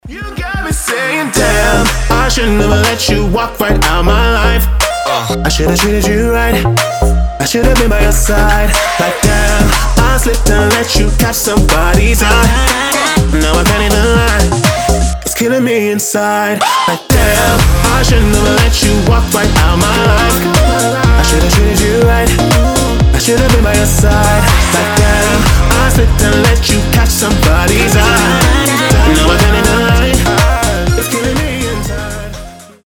мужской голос
future house
organ house